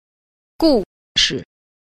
2. 故事 – gùshì – cố sự (chuyện cổ)